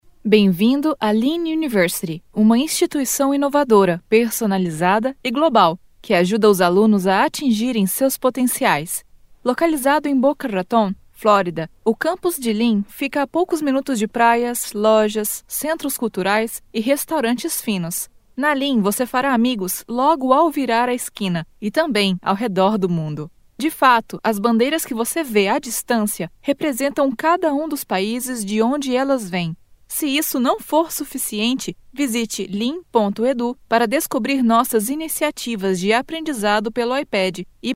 女葡01 葡萄牙语女声 巴西葡萄牙 年轻广告专题宣传 低沉|激情激昂|大气浑厚磁性|沉稳|娓娓道来|科技感|积极向上|时尚活力|神秘性感|调性走心|亲切甜美|感人煽情|素人|脱口秀